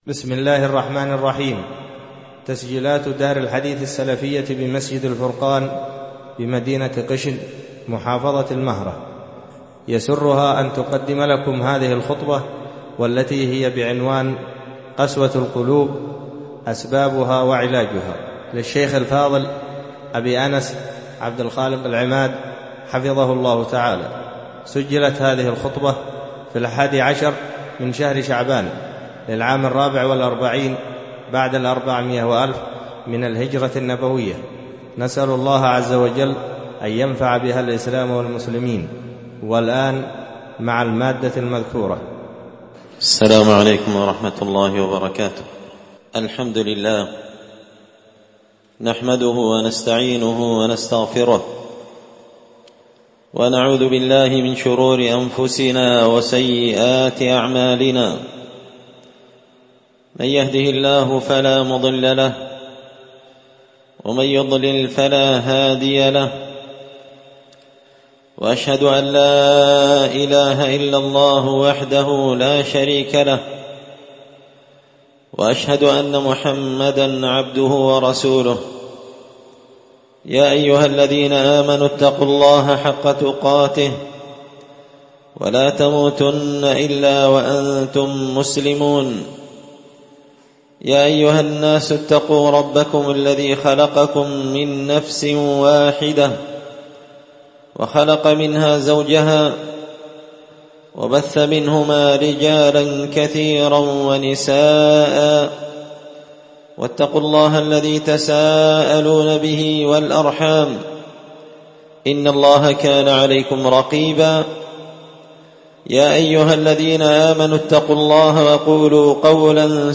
خطبة جمعة بعنوان – قسوة القلوب أسبابها وعلاجها
ألقيت هذه الخطبة بدار الحـديـث السلفية بمسجد الفرقان قشن-المهرة-اليمن